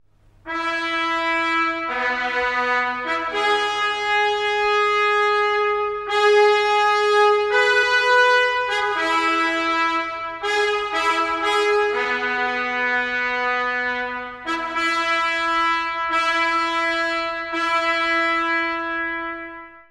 ↑古い音源なので聴きづらいかもしれません！（以下同様）
金管の景気のいいファンファーレから始まります。